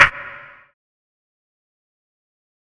snare (techniqe).wav